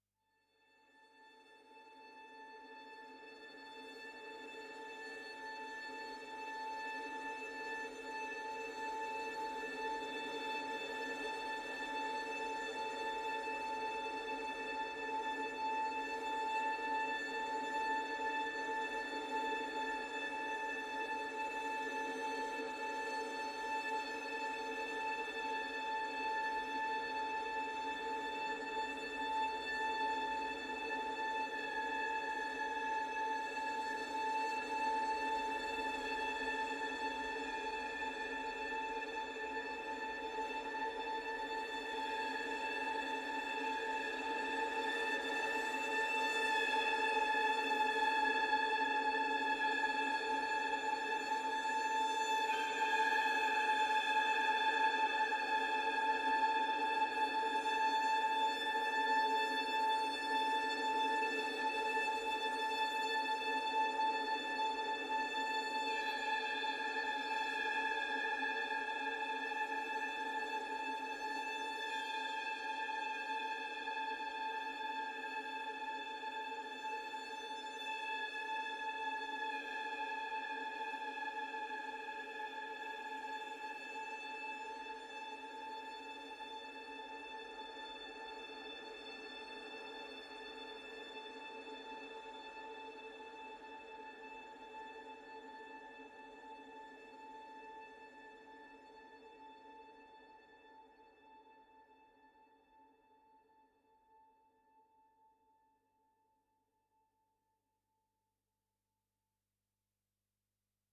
Screeching string drone form a nerve-wrecking atmosphere.